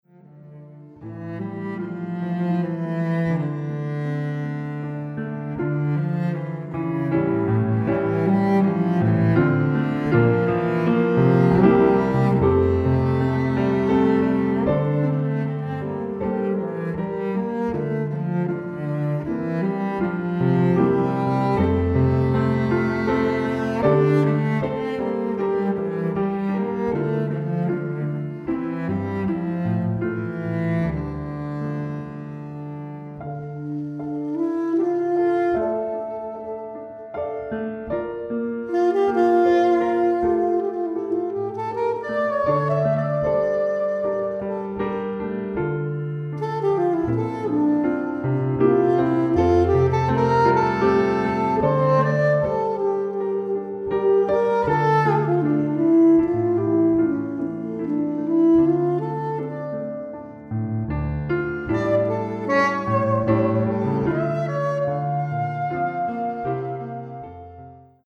Folk music, Jazz